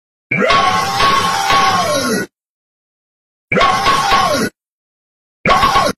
rahhh skeletons Meme Sound Effect
rahhh skeletons.mp3